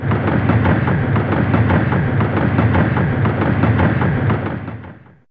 Index of /m8-backup/M8/Samples/Fairlight CMI/IIe/27Effects4
Traintrx.wav